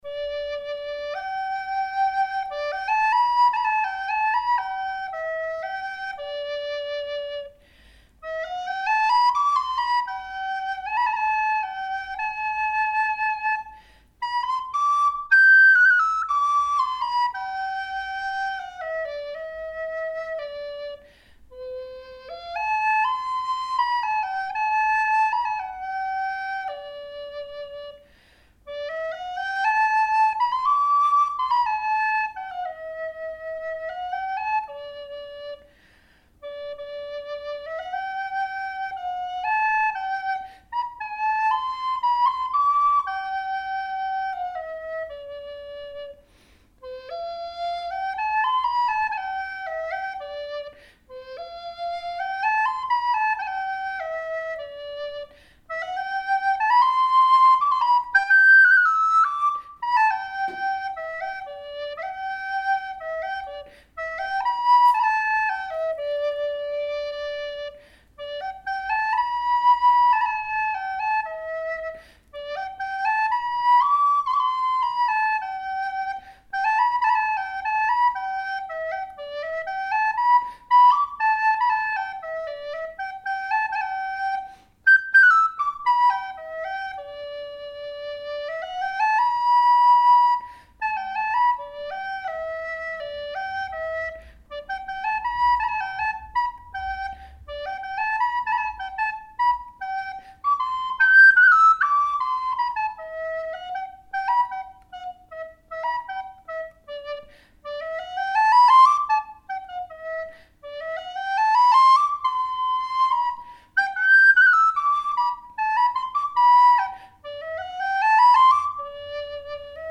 und lauschst dabei der Flötenmusik und malst dazu dein Flötenbild!
Floete-mitHall.mp3